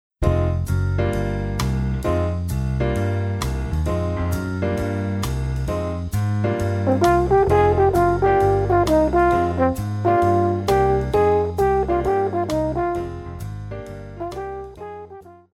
Jazz,Pop
French Horn
Band
Instrumental
Smooth Jazz,Rock
Only backing